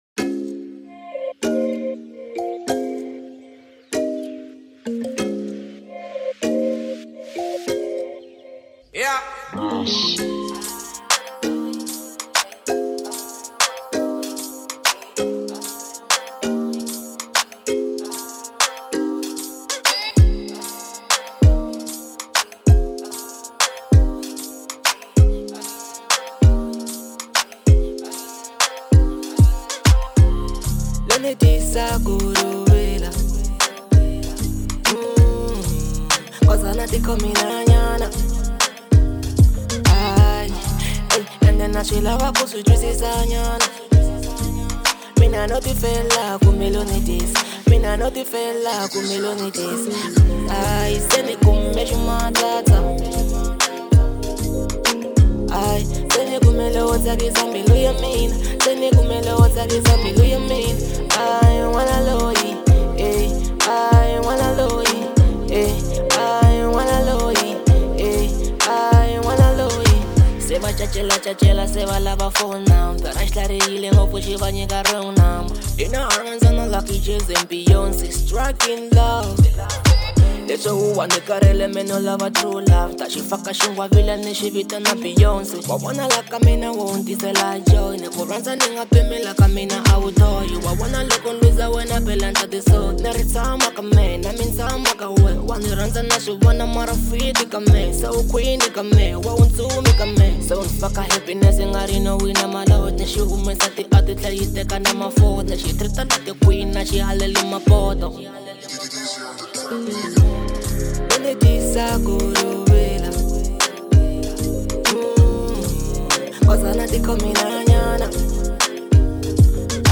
03:42 Genre : Hip Hop Size